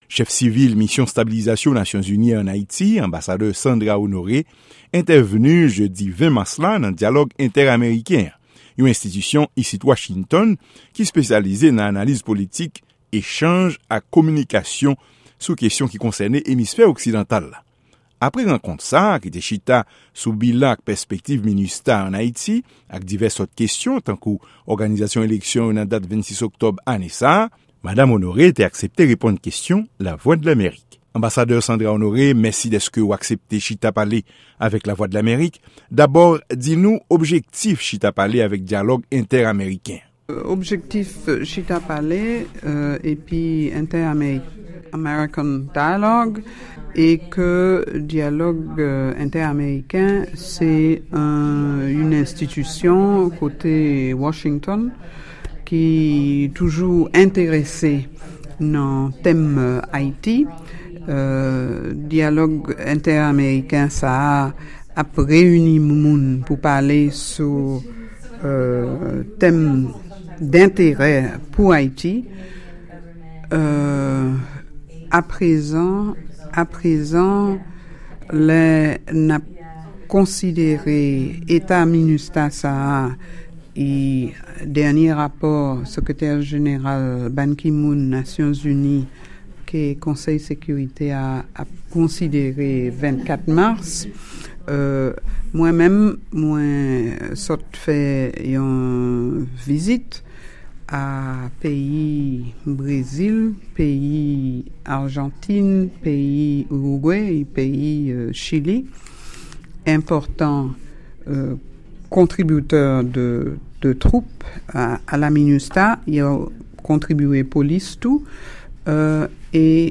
Entèvyou Chèf MINUSTAH